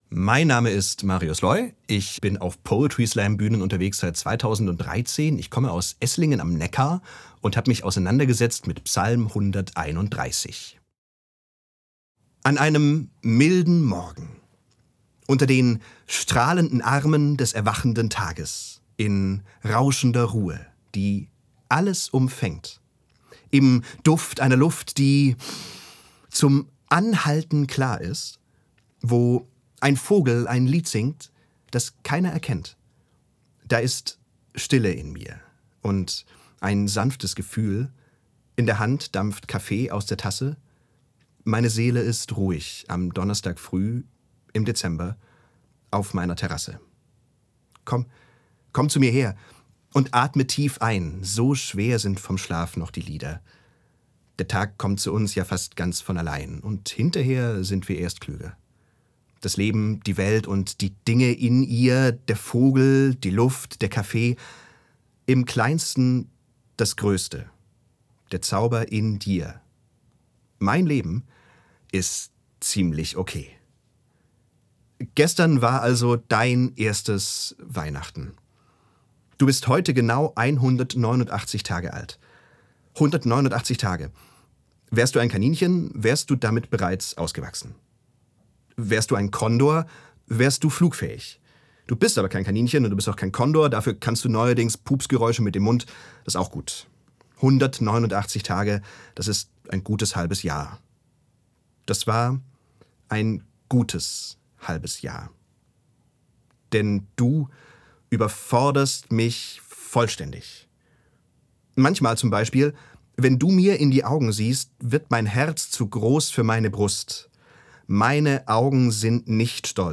Poetry Slam über Psalm 131